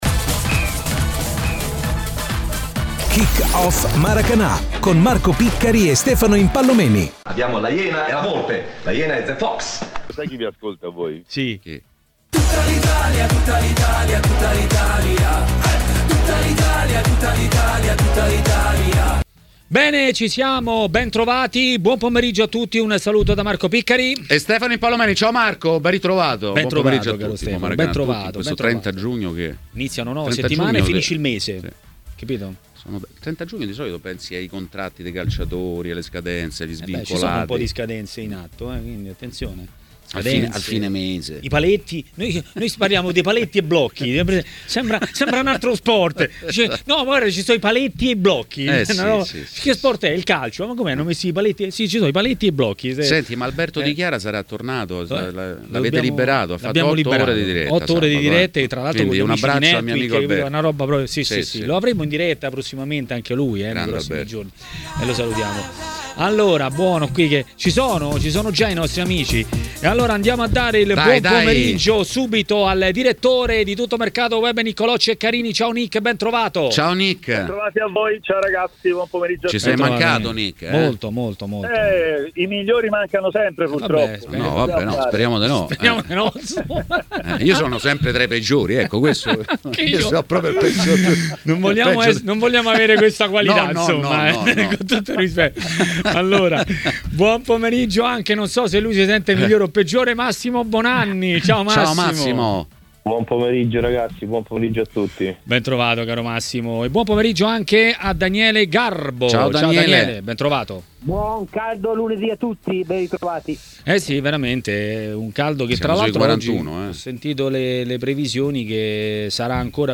A Maracanà, nel pomeriggio di TMW Radio, ha parlato il giornalista ed ex calciatore Stefano Impallomeni.